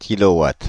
Ääntäminen
Ääntäminen France (Île-de-France): IPA: /ki.lo.wat/ Haettu sana löytyi näillä lähdekielillä: ranska Käännös Konteksti Substantiivit 1. kilowatt metrologia Suku: m .